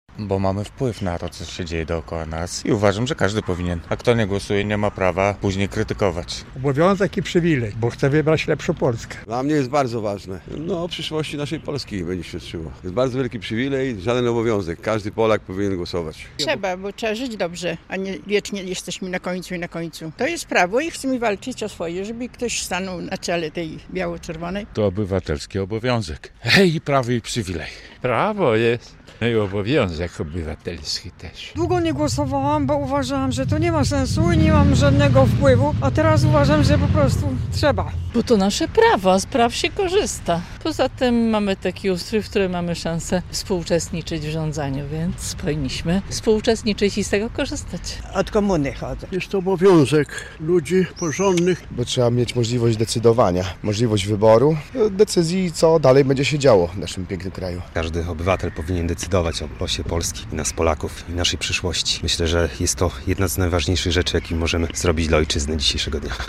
Dlaczego łomżanie biorą udział w wyborach prezydenckich? - relacja